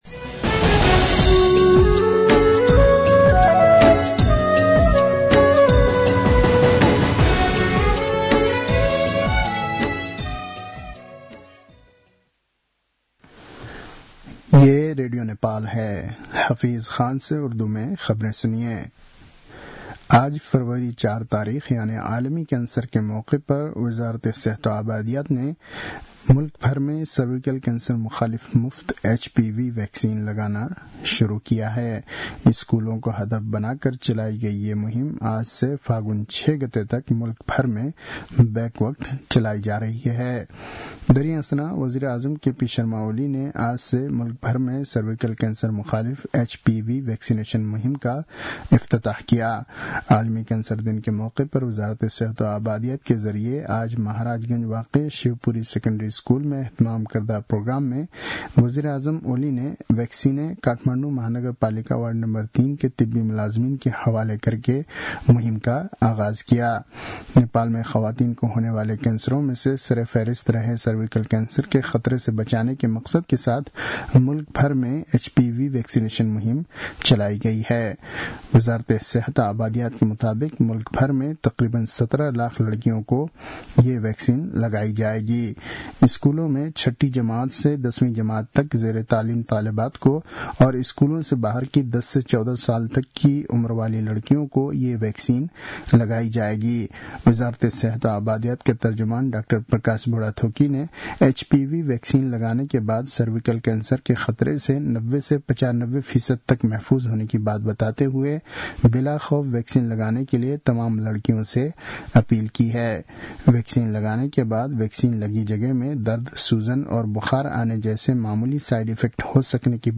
An online outlet of Nepal's national radio broadcaster
उर्दु भाषामा समाचार : २३ माघ , २०८१